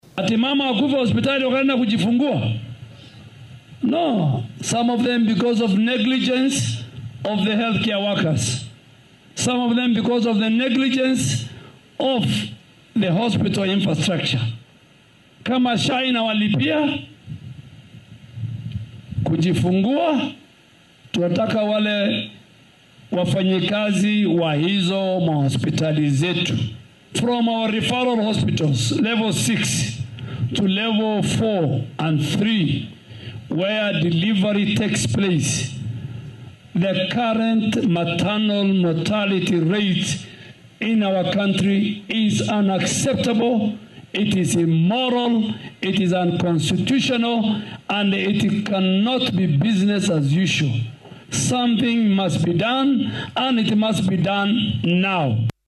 Hadalkan ayuu jeediyay xili uu xarrun isbitaal ka hirgalinayay dowlad deegaanka Laikipia.